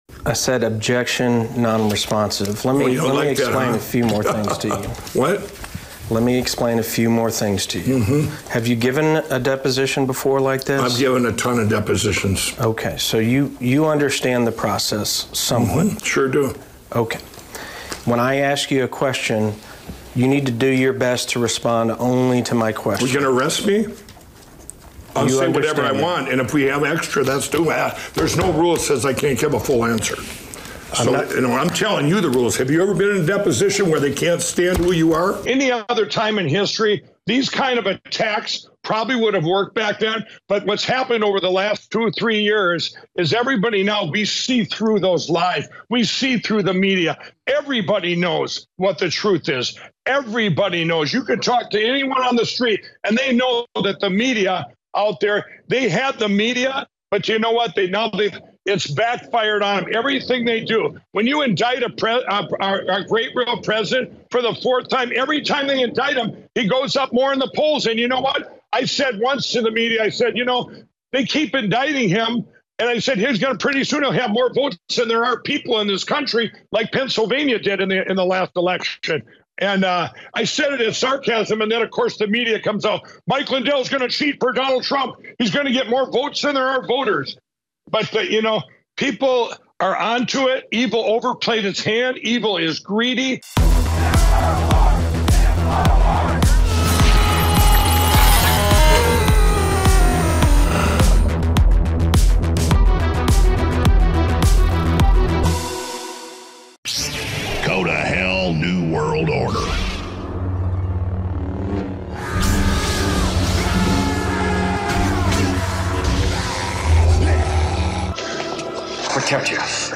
EXCLUSIVE MUST SEE INTERVIEW: Mike Lindell Responds to Leaked Deposition Footage from Frivolous Prosecution to Silence Him